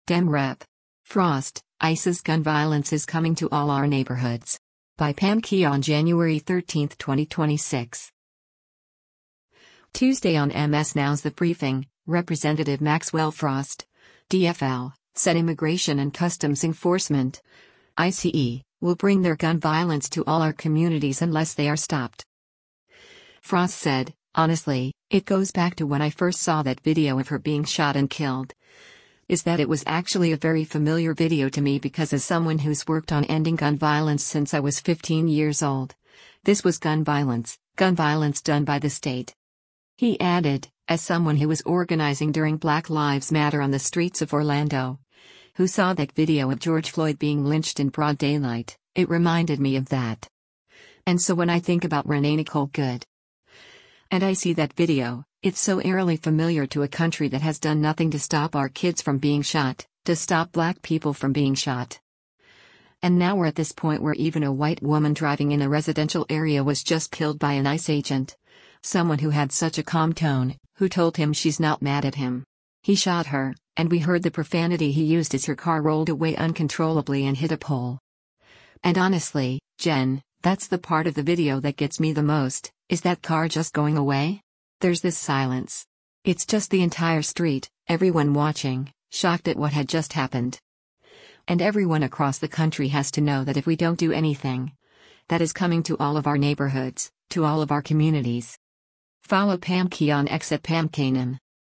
Tuesday on MS NOW’s “The Briefing,” Rep. Maxwell Frost (D-FL) said Immigration and Customs Enforcement (ICE) will bring their “gun violence” to all our communities unless they are stopped.